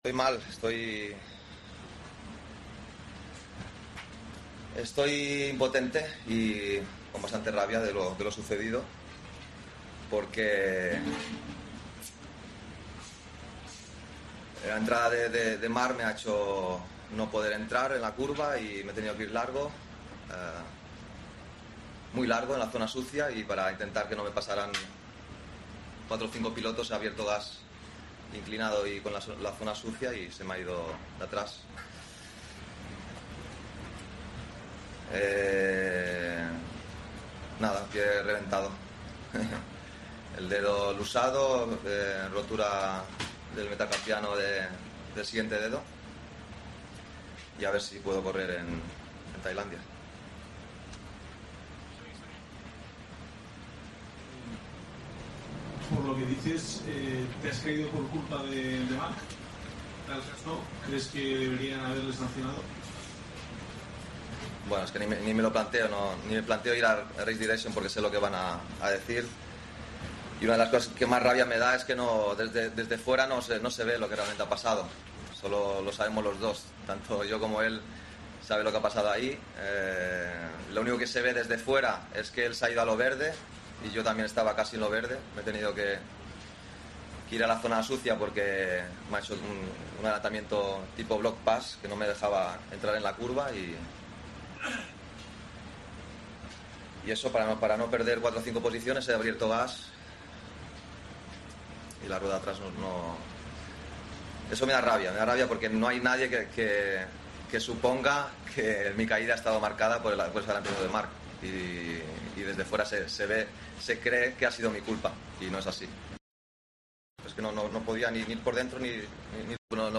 El piloto de Ducati ha declarado en rueda de prensa que Márquez ha tenido la culpa de su caída en el GP de Aragón: "Desde fuera se ve que ha sido mi culpa, y no es así"